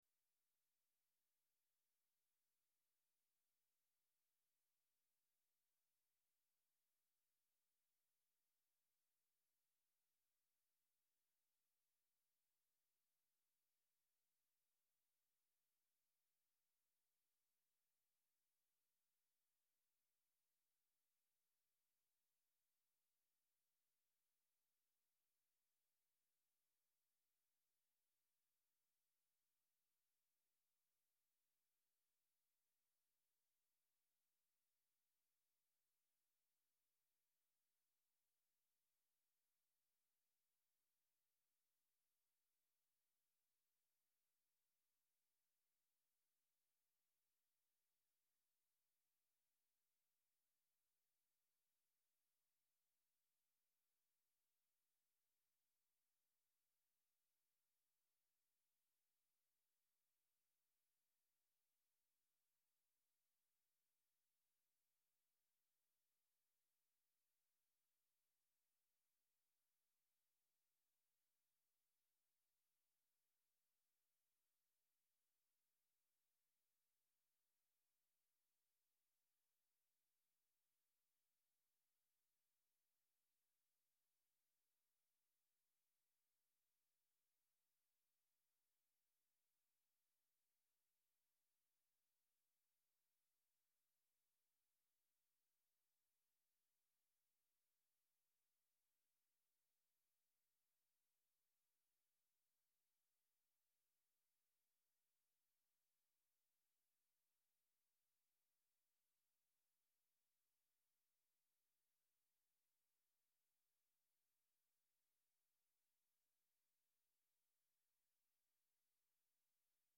Dimanche Bonheur : musette, chansons et dédicaces